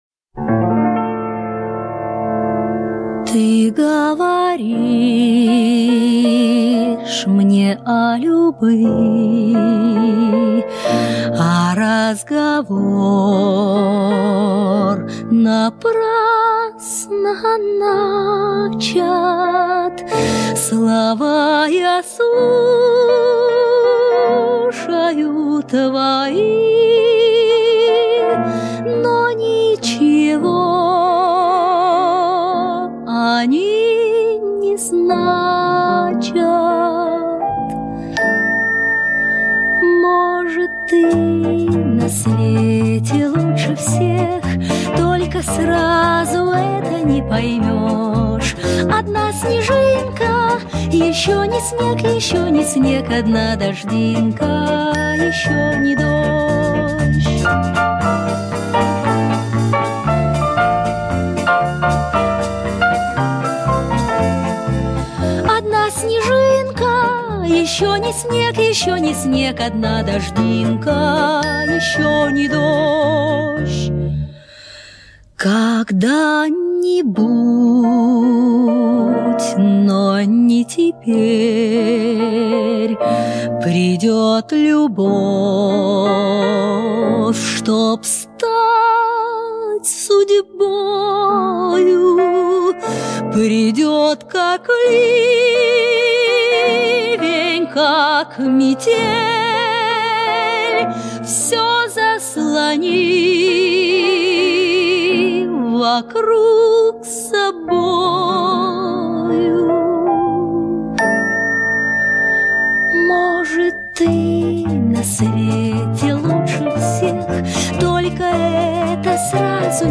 песня из фильма